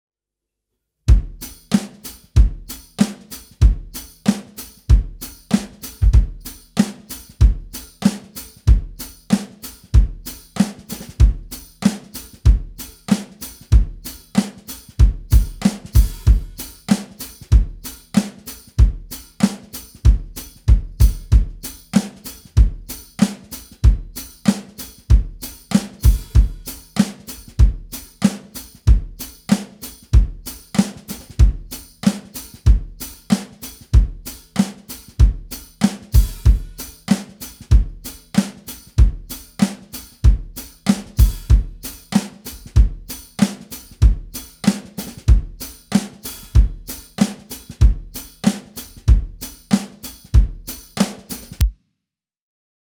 MEINL Percussion Headliner® Series HiHat Single-Row Tambourine - 5" (HTHH1B-BK)